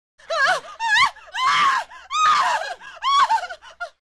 Звуки людей
Крик напуганной женщины